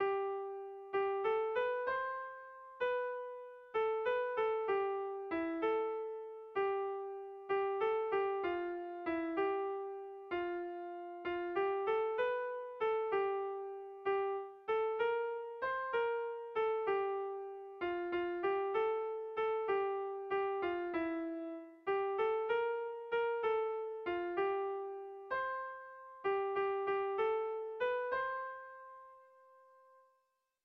Kontakizunezkoa
Zortziko txikia (hg) / Lau puntuko txikia (ip)
ABDD